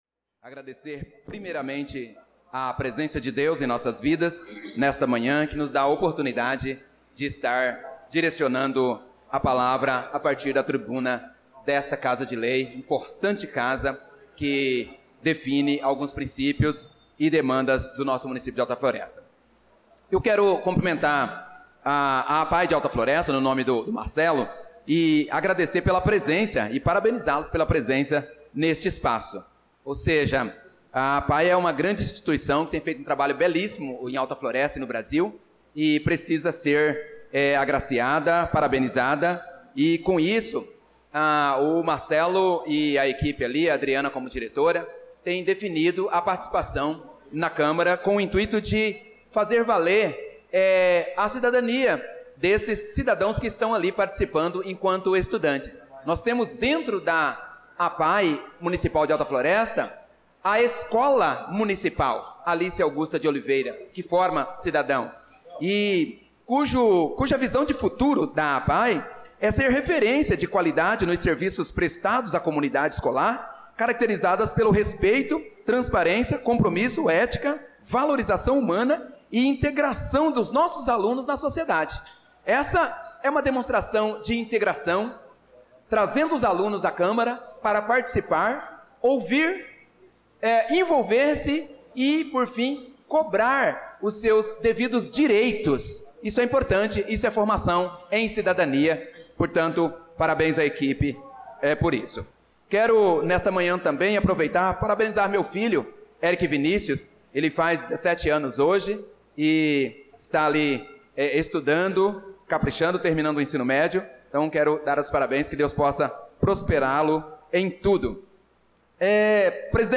Pronunciamento do vereador Pro. Nilson na Sessão Ordinária do dia 18/02/2025